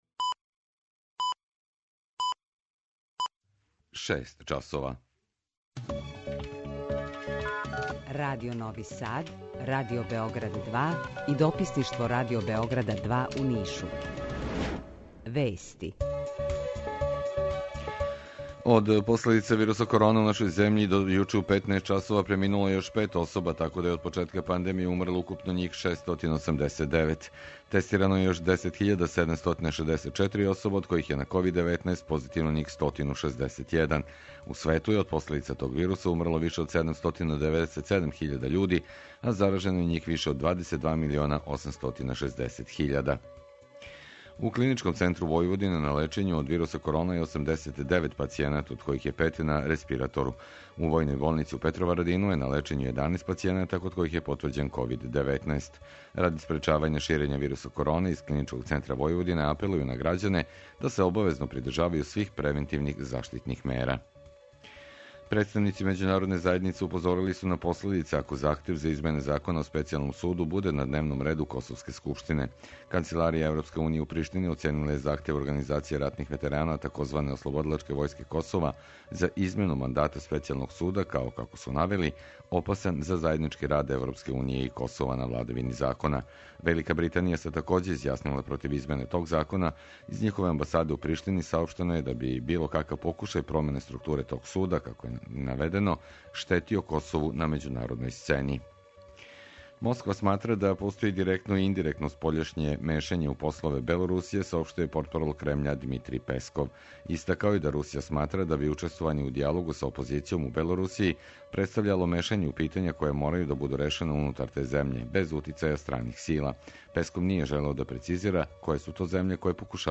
Укључење из Бања Луке
Јутарњи програм из три студија